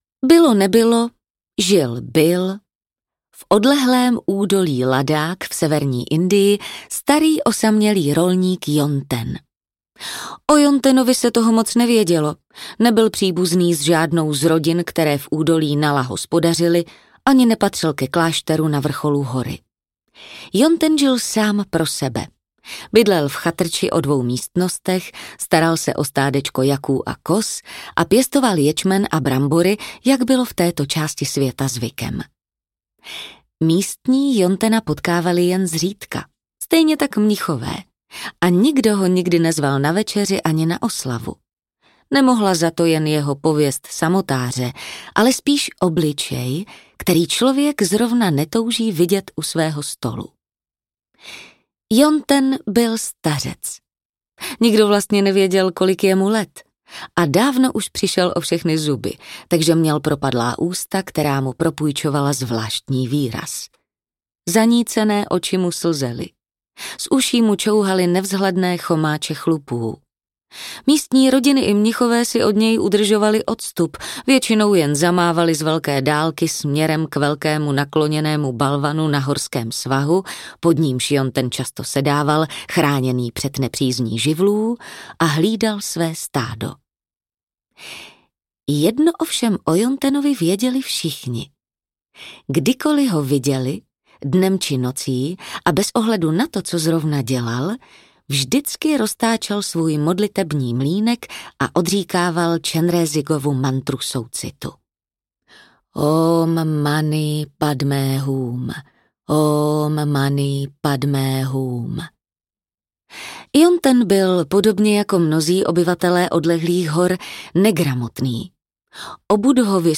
Buddhovy příběhy na dobrou noc audiokniha
Ukázka z knihy